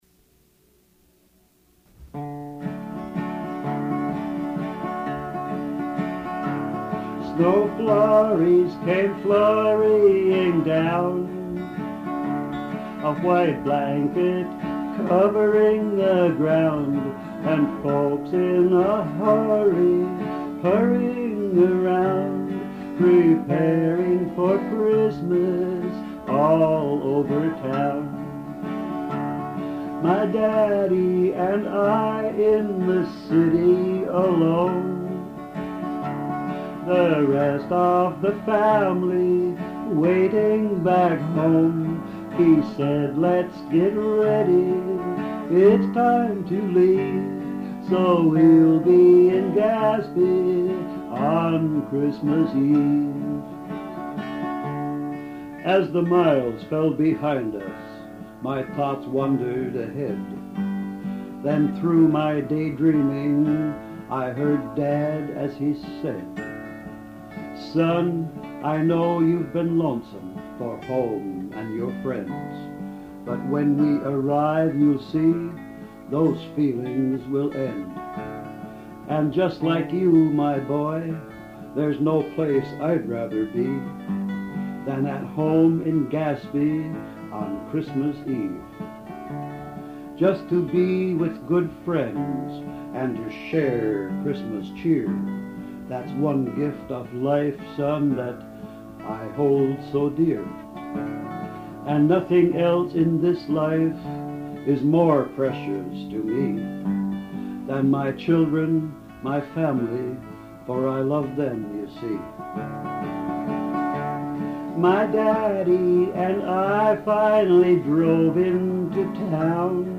home recording